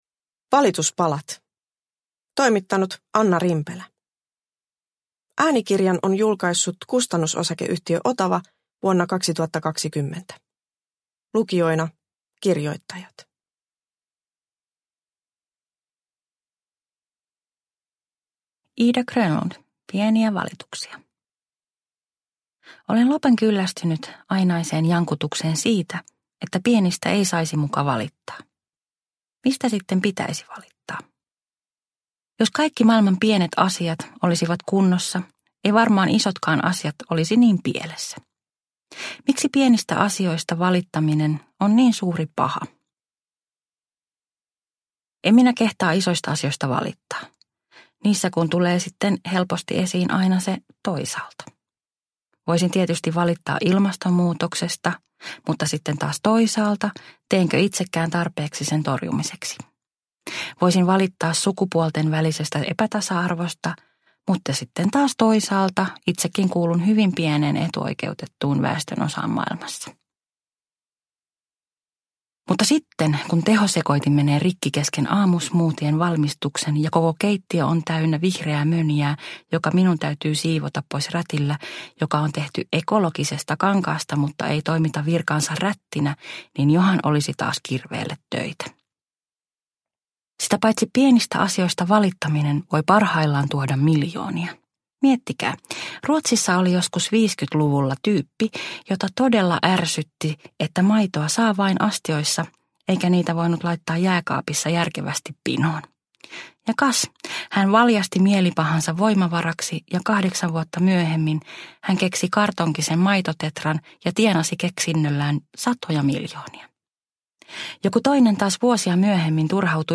Valituspalat – Ljudbok